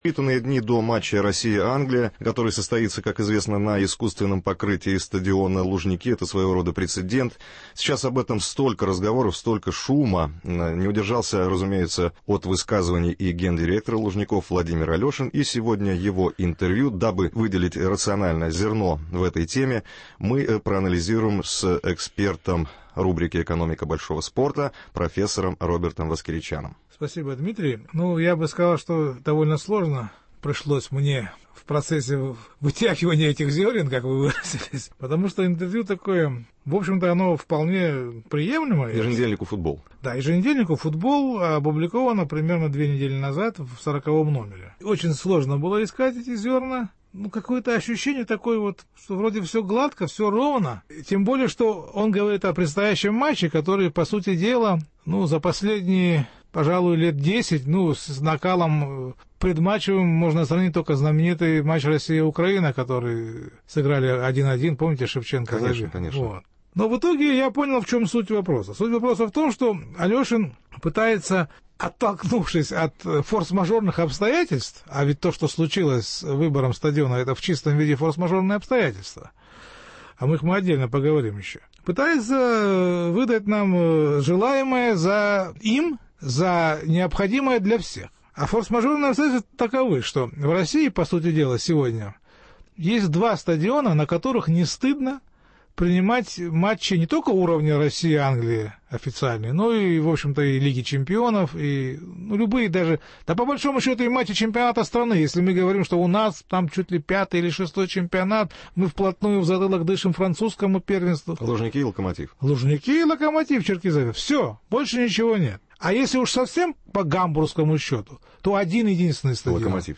Экономика большого спорта. Интервью